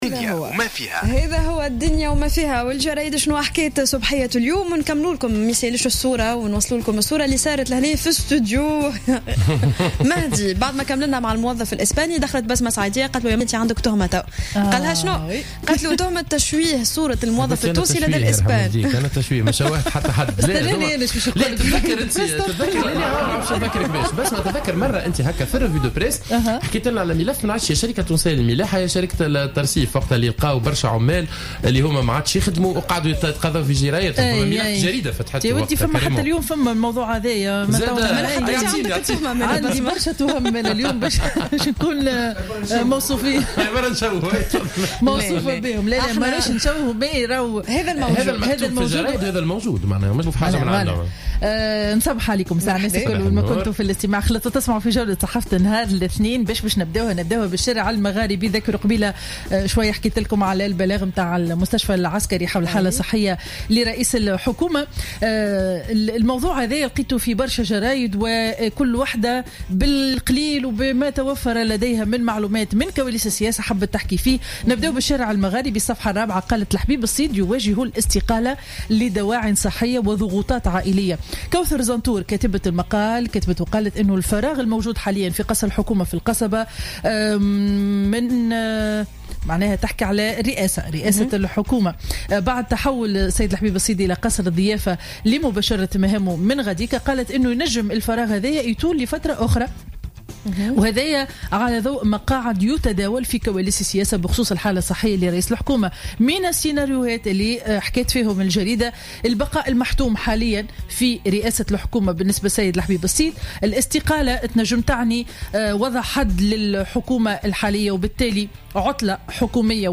Revue de presse du lundi 15 Février 2016